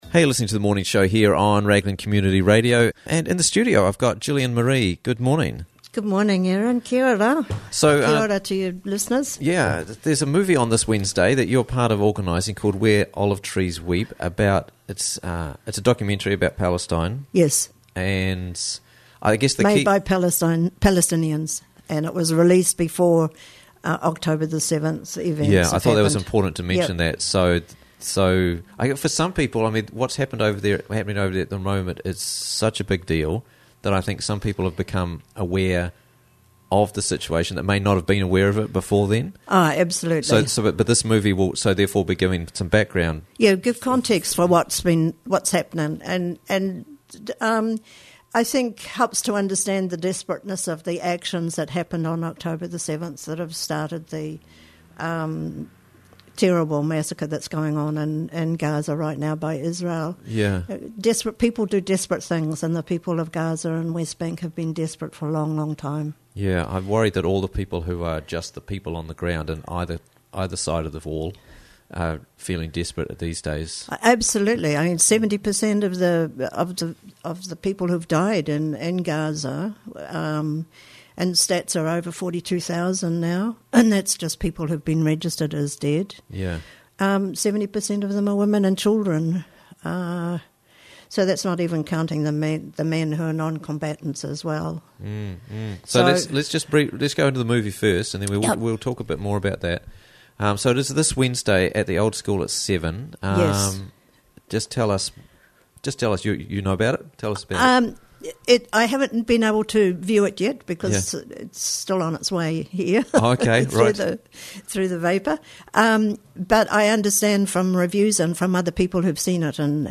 Palestine Film Wednesday Night - Interviews from the Raglan Morning Show